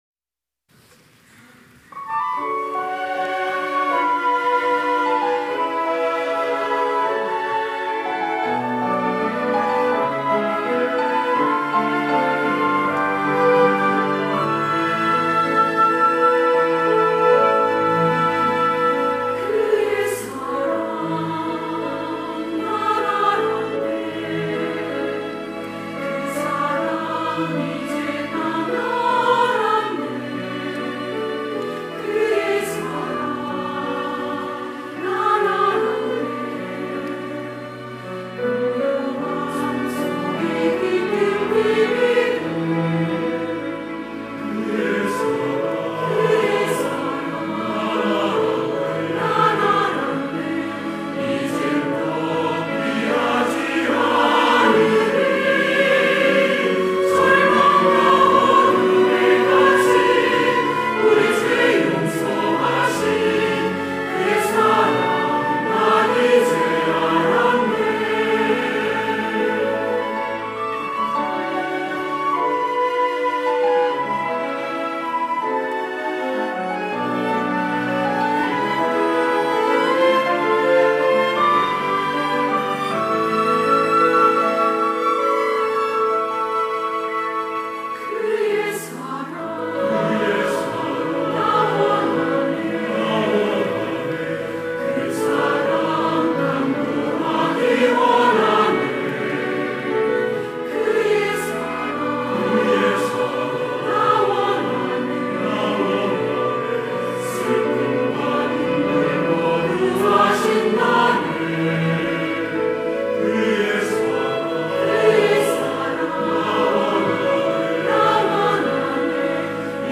할렐루야(주일2부) - 그 사랑 이제 난 알았네
찬양대